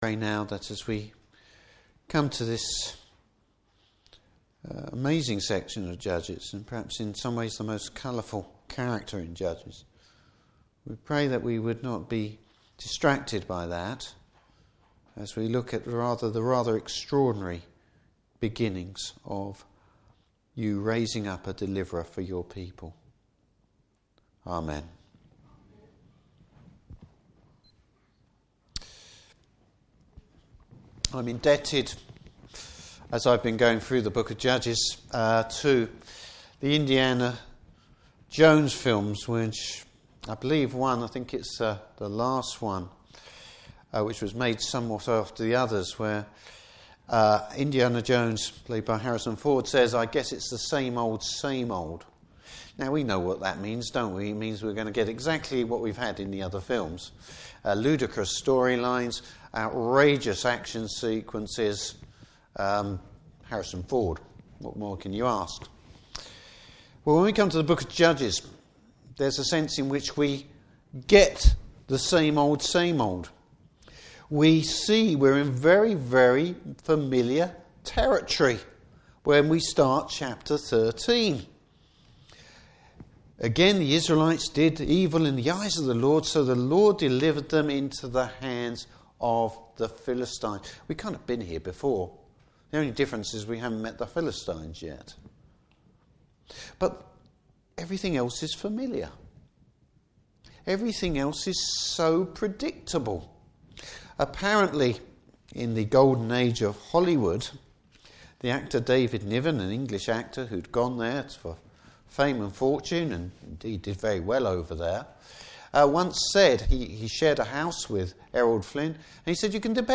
Service Type: Evening Service Bible Text: Judges 13.